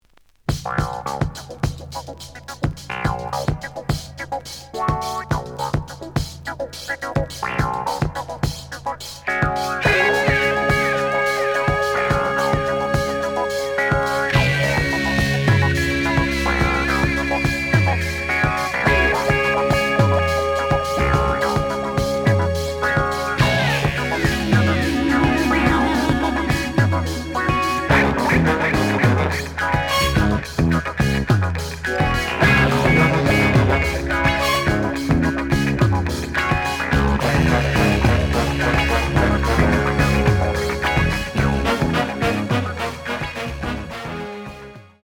The audio sample is recorded from the actual item.
●Genre: Soul, 70's Soul
Slight cloudy on both sides.